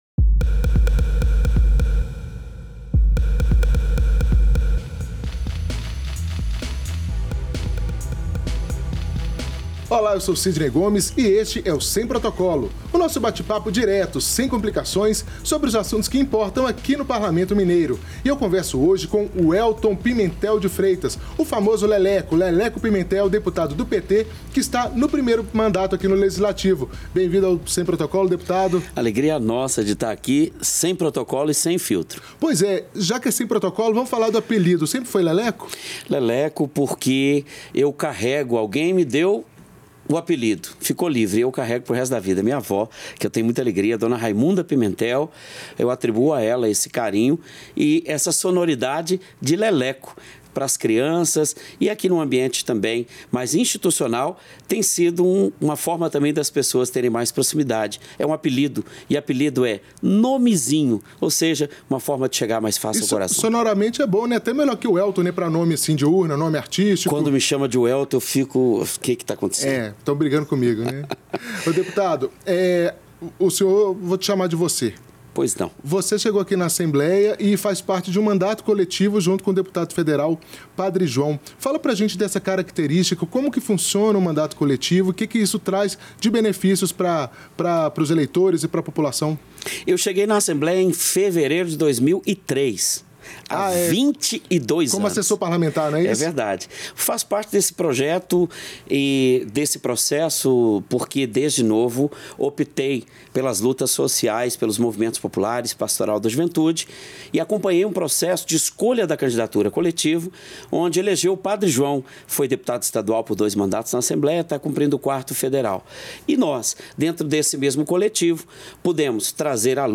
Na conversa